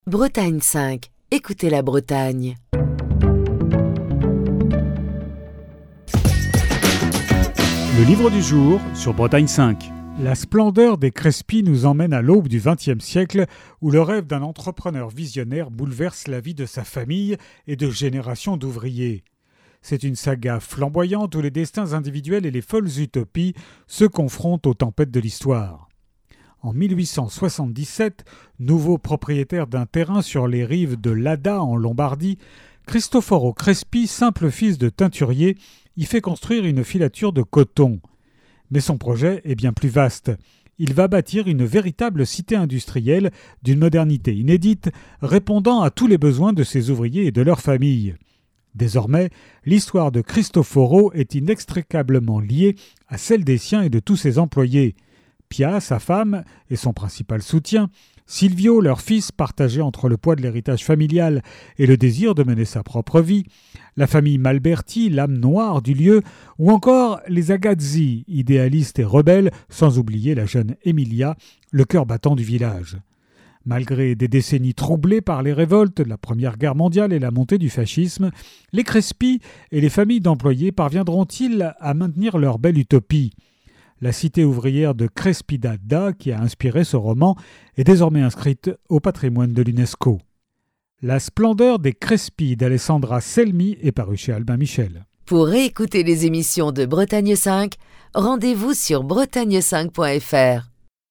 Chronique du 14 juin 2024.